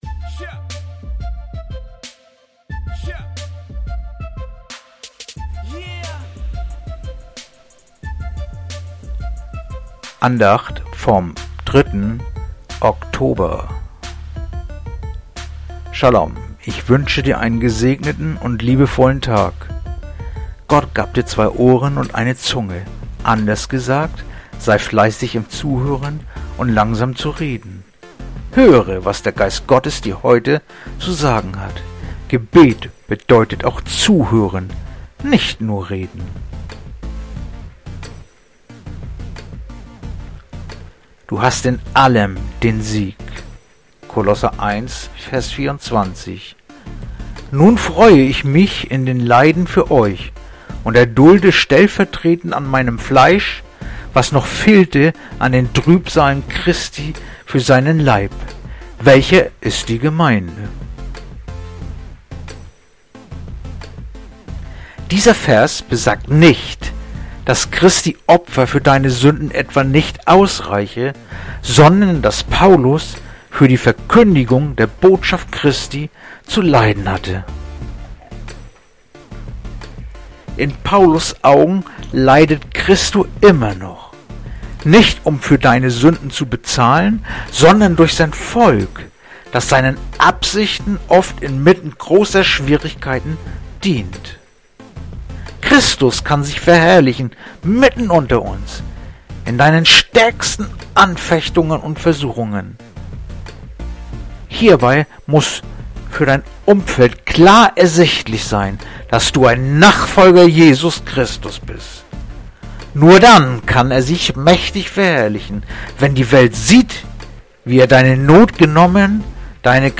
Andacht-vom-03-Oktober-Kolosser-1-24.mp3